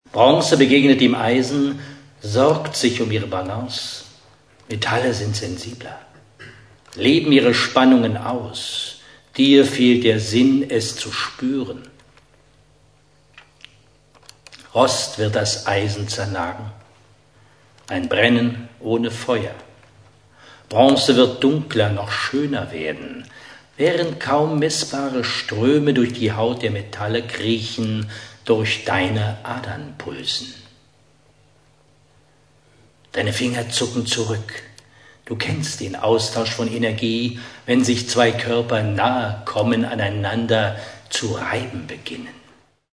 Die mit "Audioclip" gekennzeichneten mp3-Stücke enthalten kleine Ausschnitte aus dem literarisch-musikalischen Programm